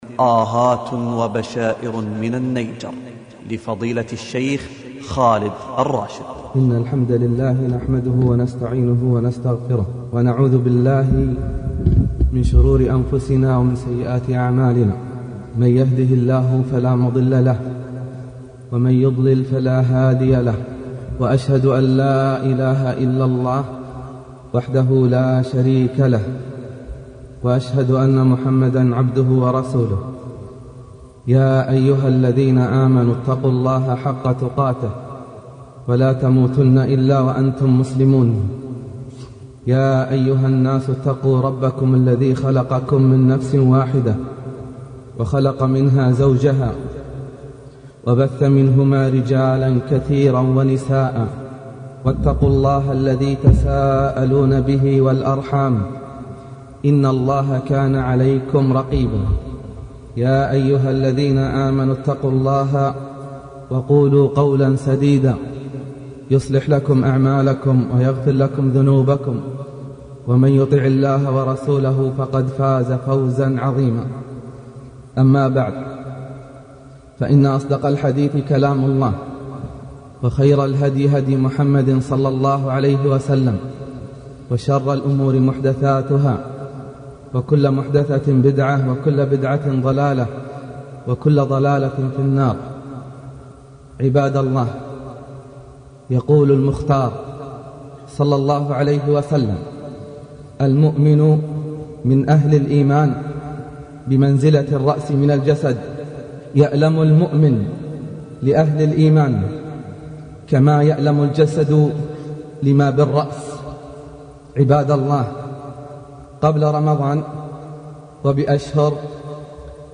باب أول: مقدمة الخطبة والتحذير الديني
المحاضرات الصوتية